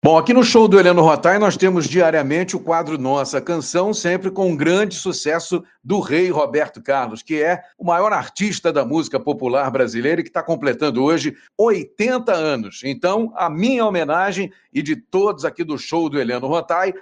Em entrevista exclusiva à Super Rádio Tupi